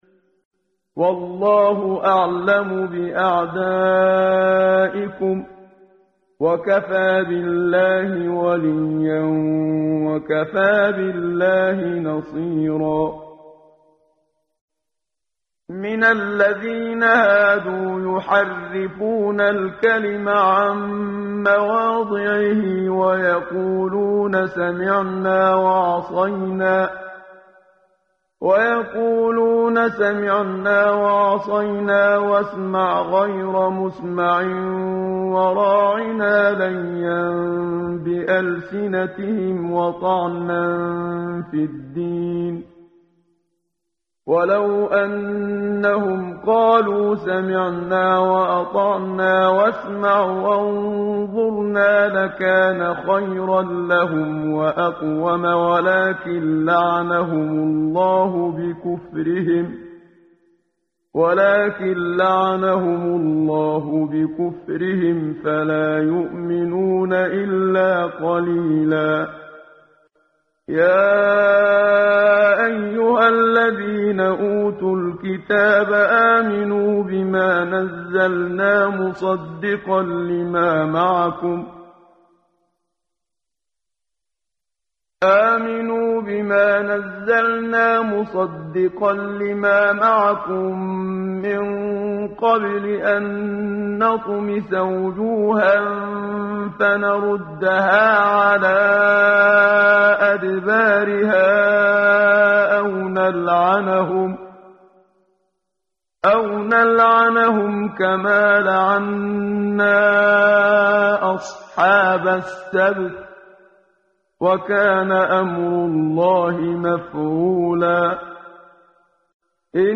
ترتیل صفحه 86 سوره مبارکه سوره نساء (جزء پنجم) از سری مجموعه صفحه ای از نور با صدای استاد محمد صدیق منشاوی
quran-menshavi-p086.mp3